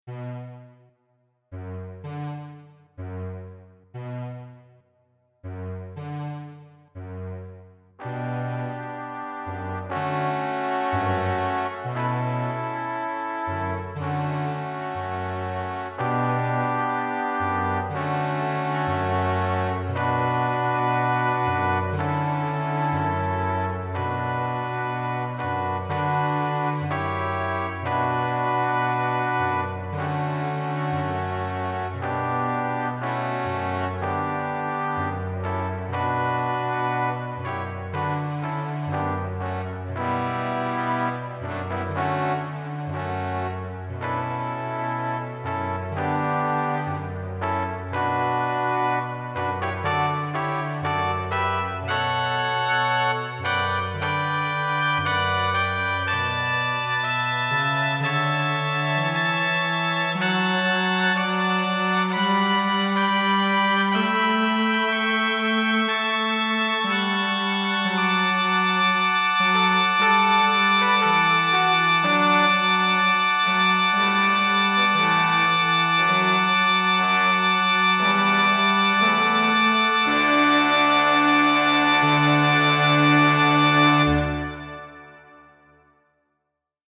Another Christmas piece - this time an arrangement of the 'Good King Wenceslas' tune with a habanera-style rhythm.
wenceslas-fanfare.mp3